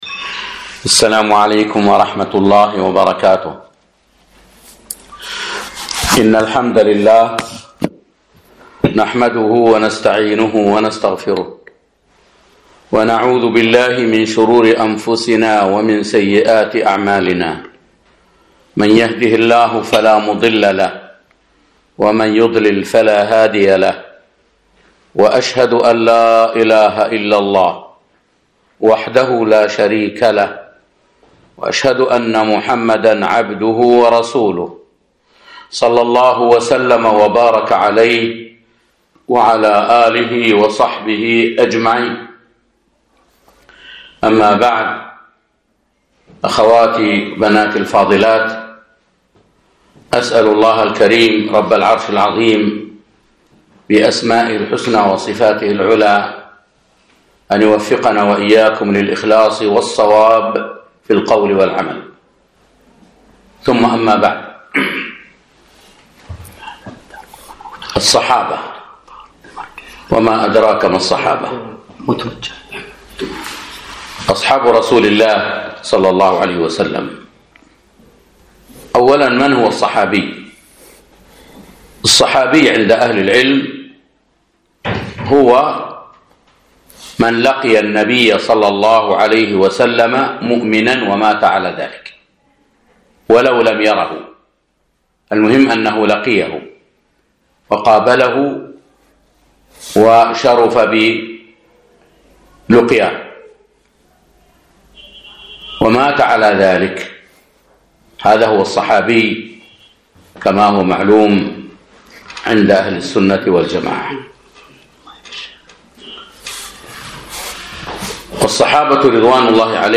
فضائل الصحابة ومكانتهم - محاضرة